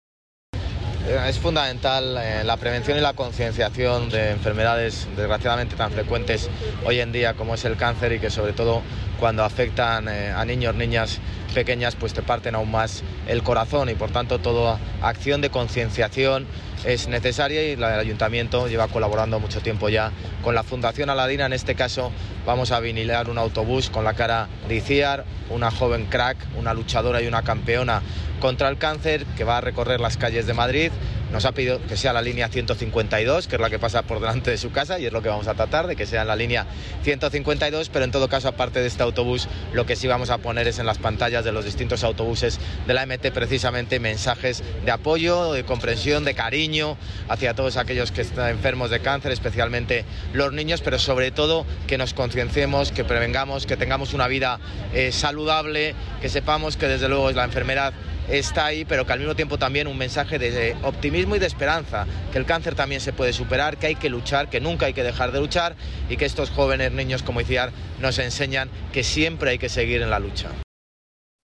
El alcalde ha participado hoy, Día Mundial del Cáncer Infantil, en la presentación de la campaña con la que la EMT se une al homenaje de la Fundación Aladina
Nueva ventana:José Luis Martínez-Almeida, alcalde de Madrid